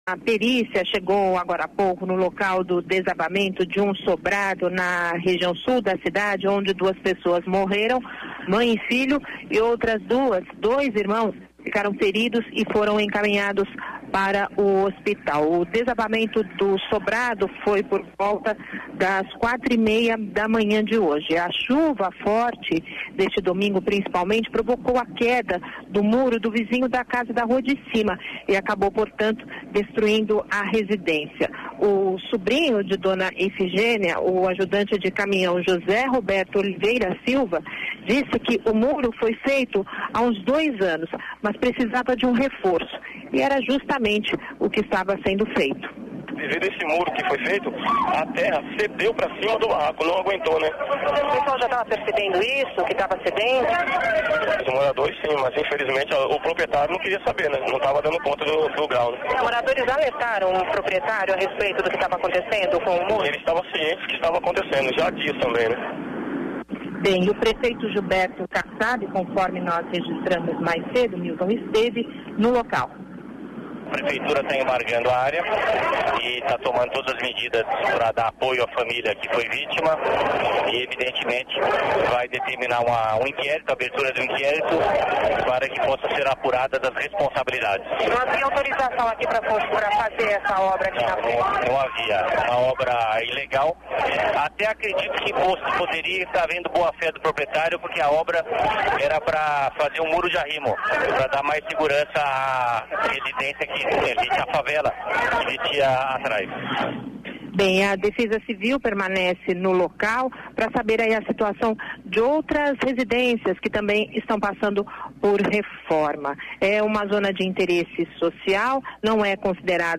Ouça trecho da reportagem